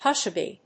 音節hush・a・by 発音記号・読み方
/hˈʌʃəbὰɪ(米国英語)/